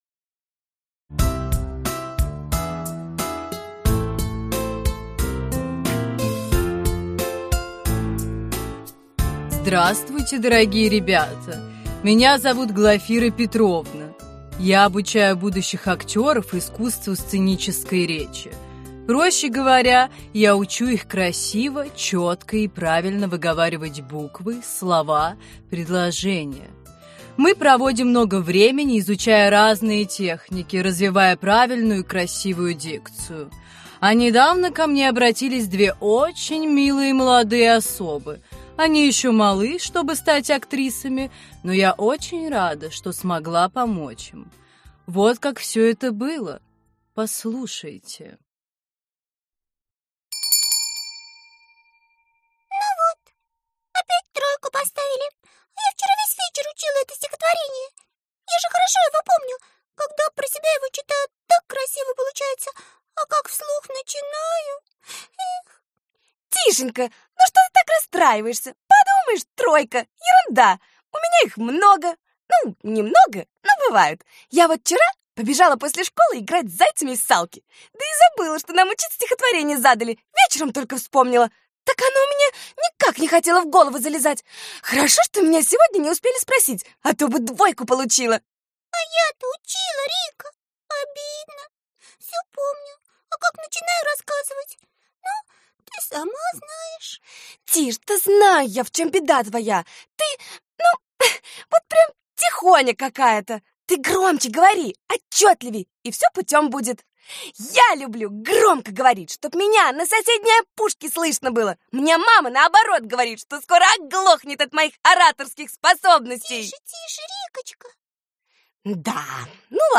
Аудиокнига Развивающие скороговорки | Библиотека аудиокниг